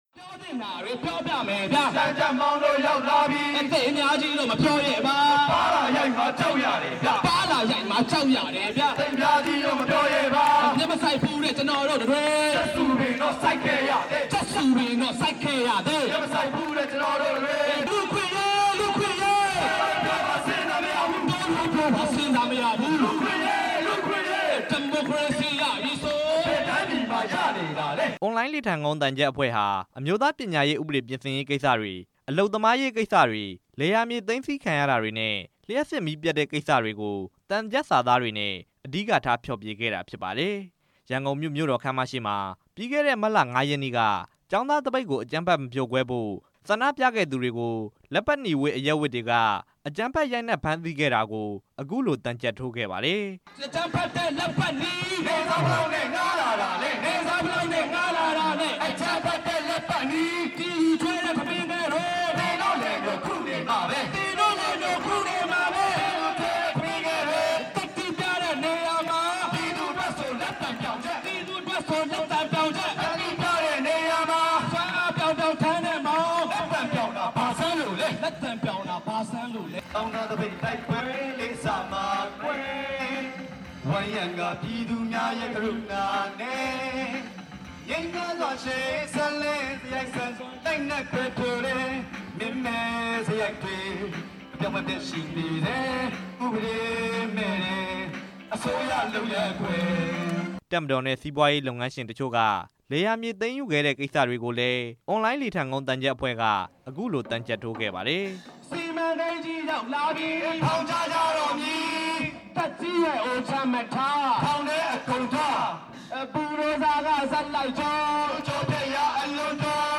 ရန်ကုန်မြို့ ဗဟန်းမြို့နယ် ဗိုလ်စိန်မှန်အားကစားကွင်းရှေ့က အမျိုးသားဒီမိုကရေစီအဖွဲ့ချုပ် သြင်္ကန်ဖျော်ဖြေရေး မဏ္ဍပ်မှာ အွန်လိုင်းလေထန်ကုန်းသံချပ် အဖွဲ့ဟာ မနေ့ညက သံချပ်ထိုးဖျော်ဖြေခဲ့ပါတယ်။